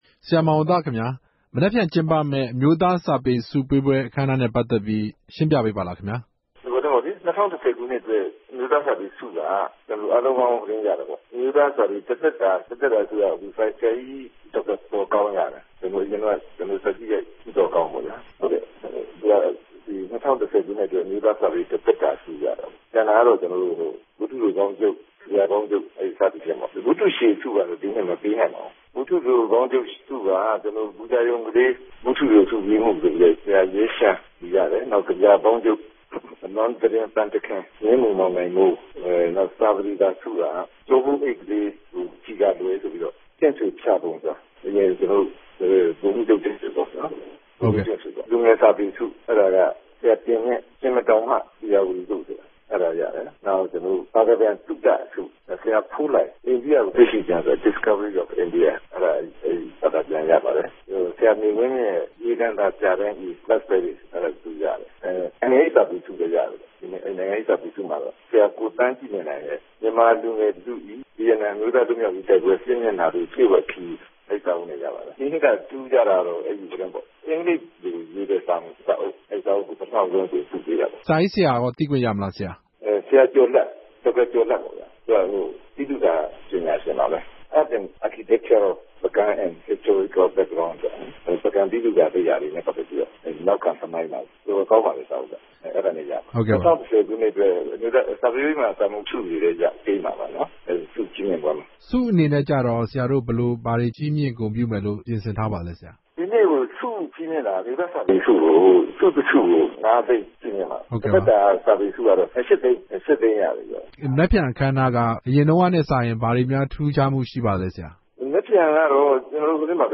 အမျိုးသား စာပေဆုအပေါ် ဆရာမောင်ဝံသနဲ့မေးမြန်းချက်